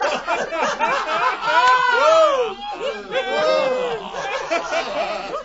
crowd_laugh3_p2.wav